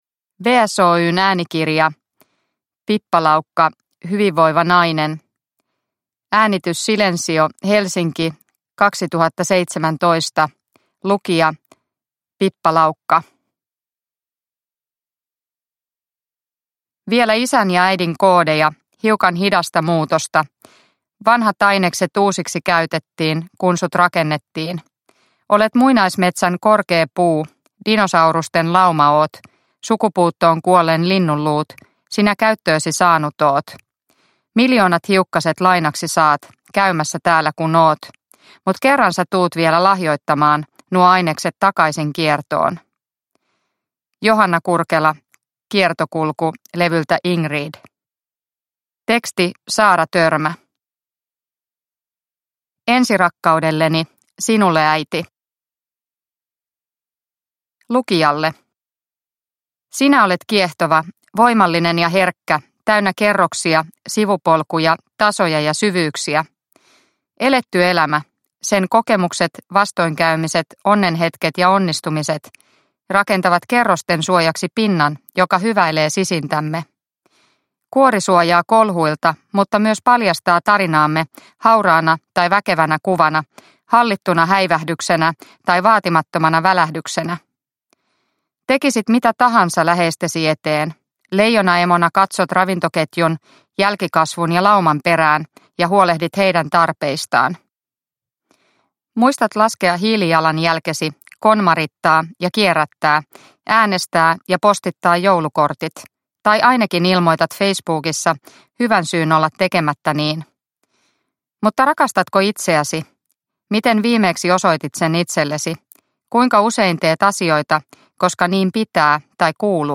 Hyvinvoiva nainen – Ljudbok – Laddas ner